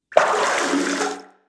WaterSplash_Out_Short2.wav